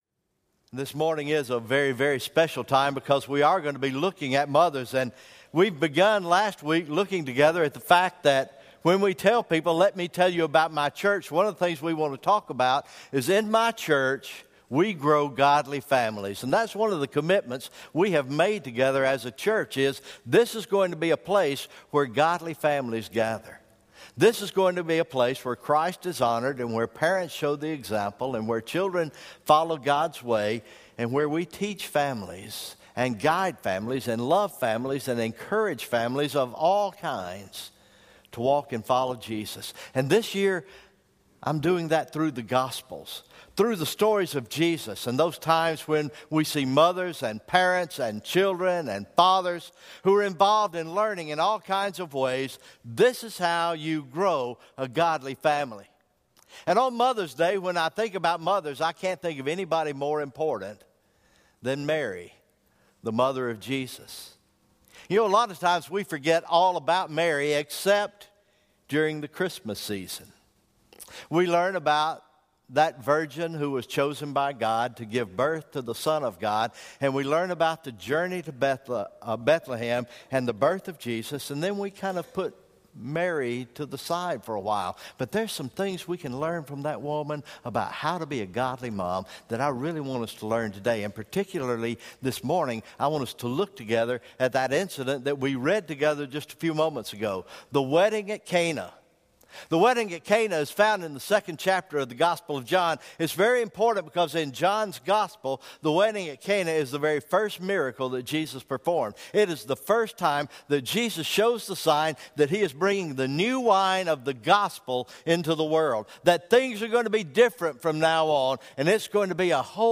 May 14, 2017 Morning Worship Service
Sermon Recordings